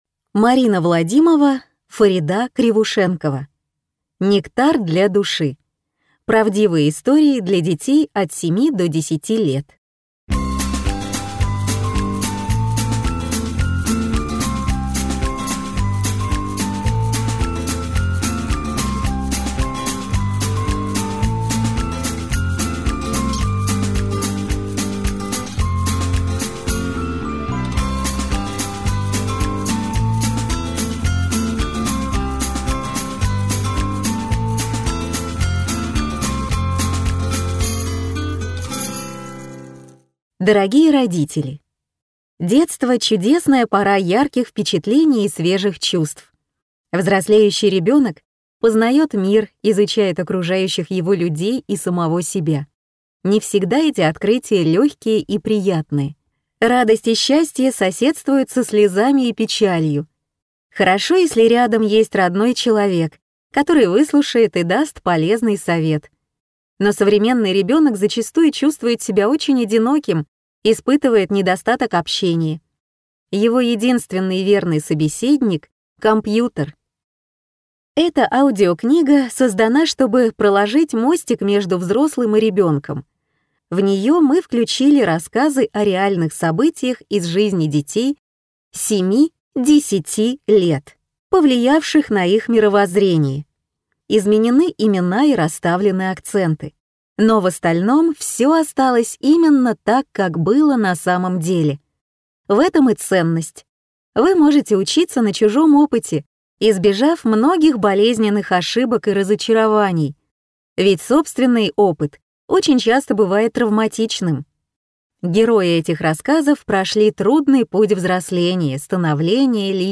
Аудиокнига Нектар для души. Правдивые истории для детей от 7 до 10 лет | Библиотека аудиокниг